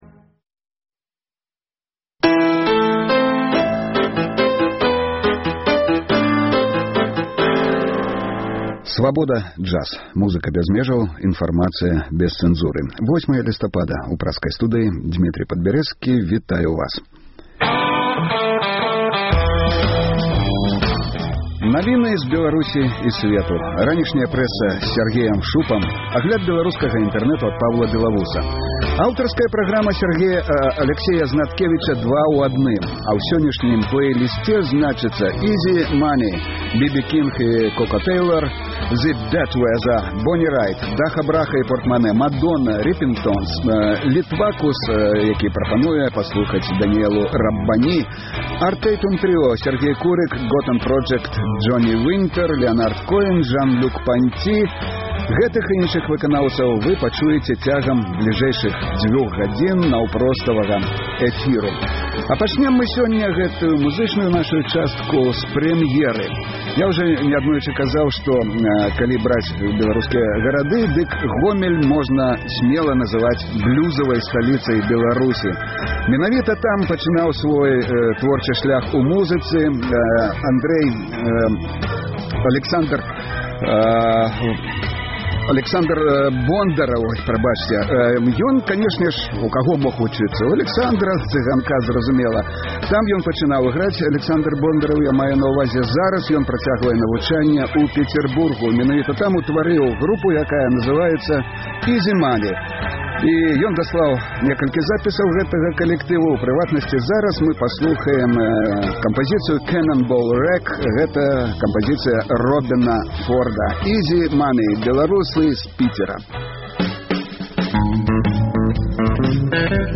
Слухайце ад 12:00 да 14:00 жывы эфір "Свабоды"!